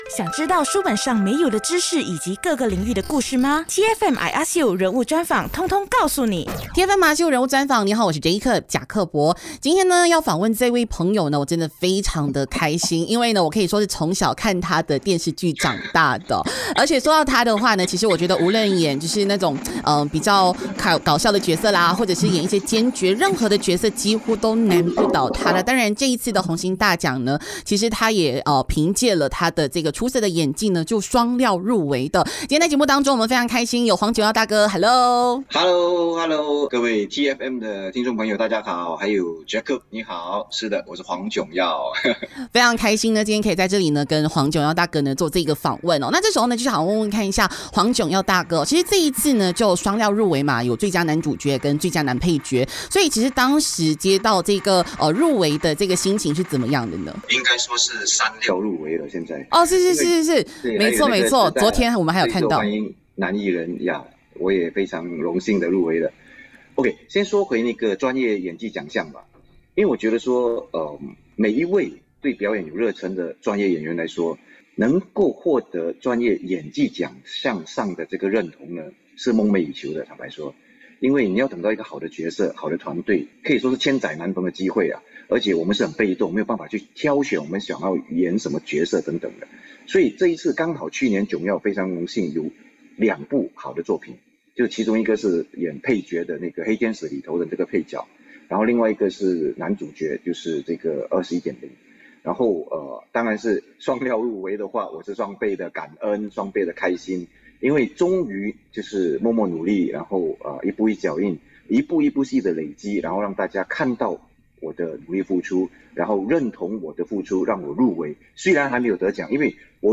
0322 TEA FM  I ASK U 人物专访 新传媒艺人 黄炯耀.mp3